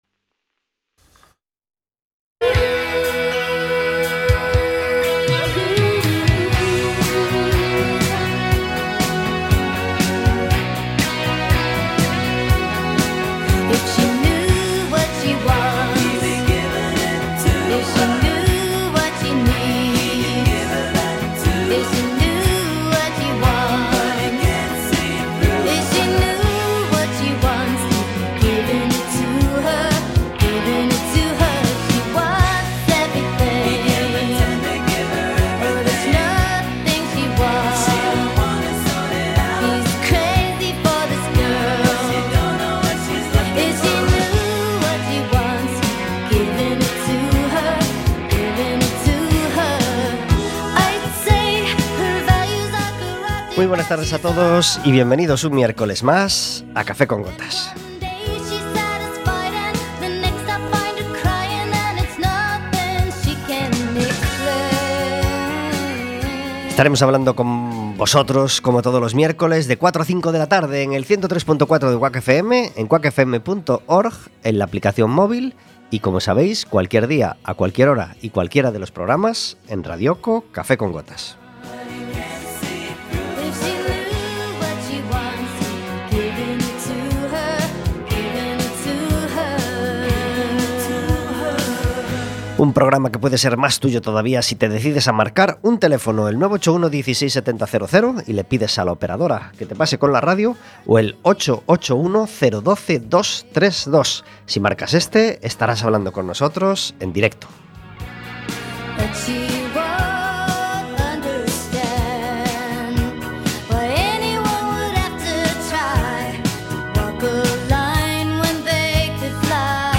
Un invitado cada día